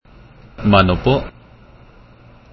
(If you want to hear what Mano Po sounds like, click on the PLAY button below)
mano-po.mp3